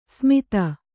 ♪ smita